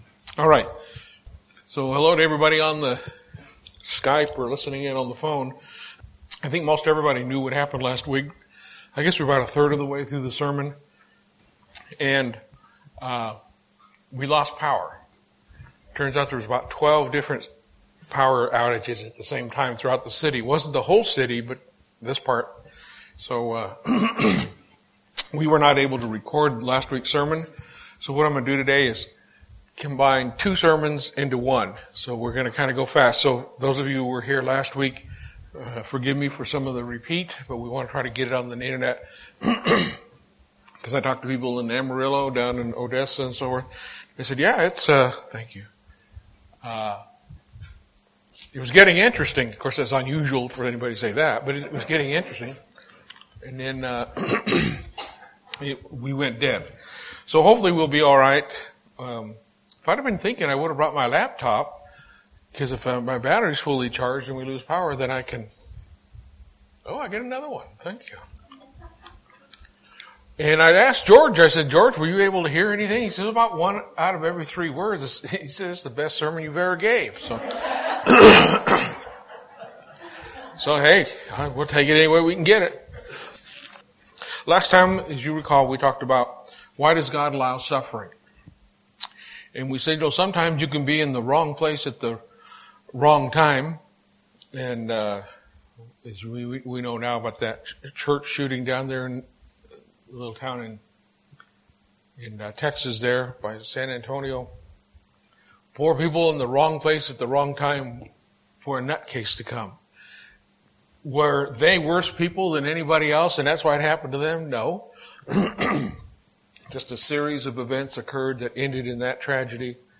Listen to this sermon to understand why Christians suffer.